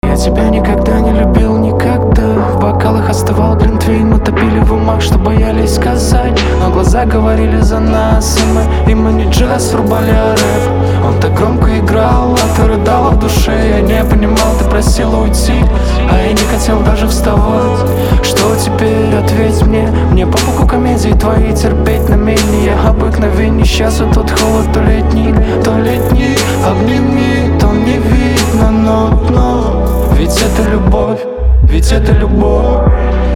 • Качество: 320, Stereo
мужской вокал
громкие
Хип-хоп
русский рэп